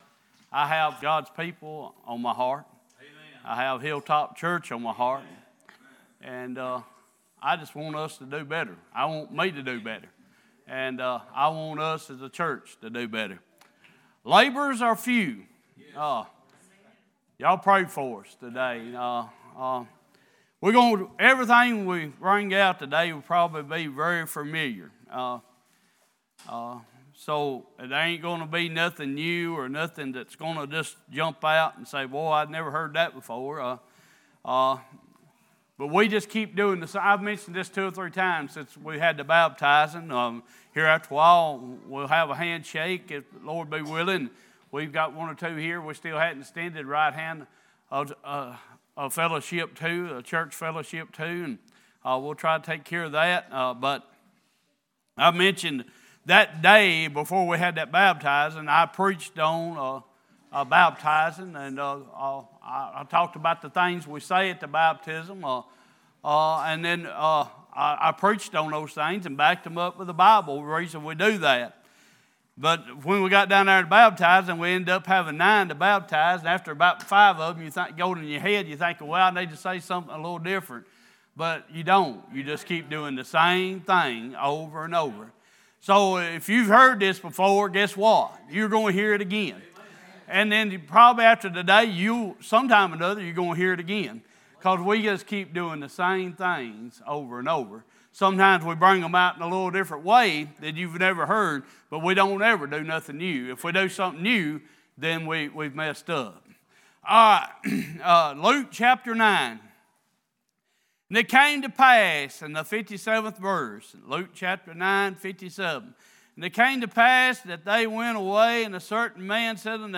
Sunday Morning Passage: Luke 9:57-62, Matthew 9:36-38 Service Type: Worship « A Study of Ephesians